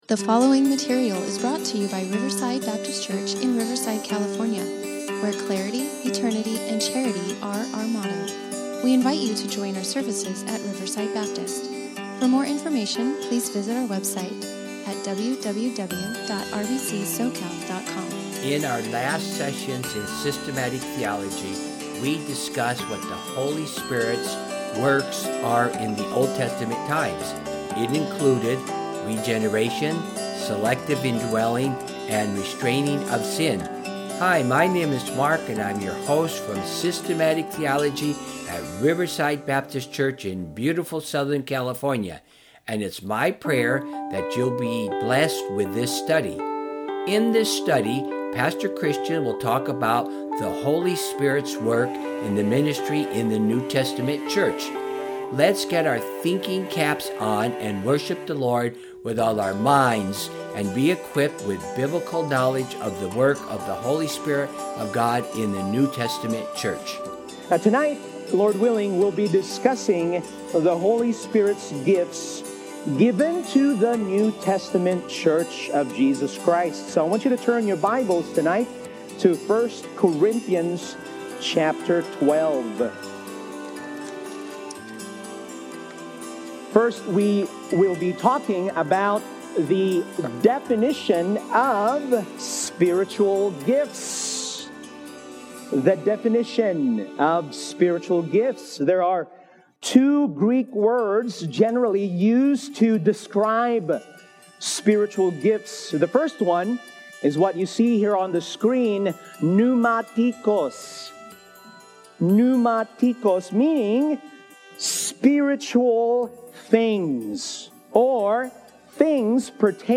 Pneumatology: The Gifts of the Holy Spirit to the New Testament Church (Part 1 of 2) – Riverside Baptist Church Sermons